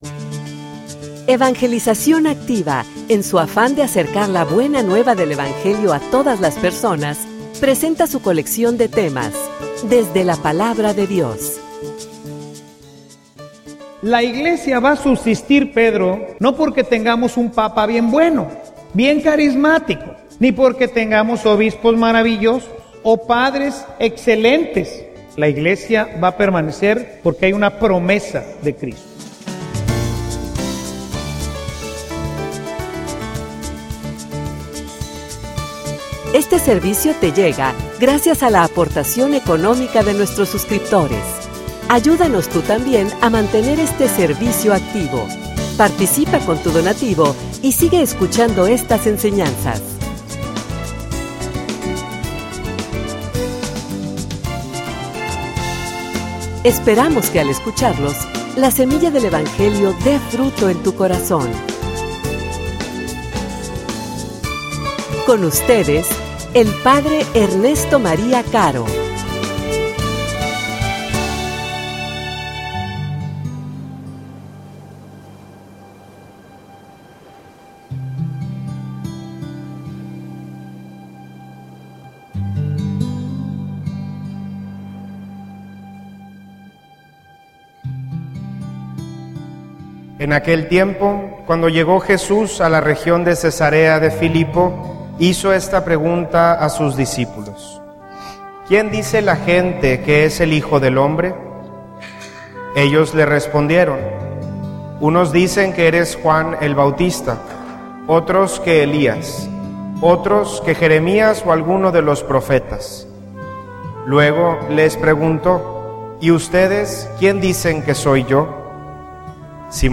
homilia_Las_promesas_de_Jesus.mp3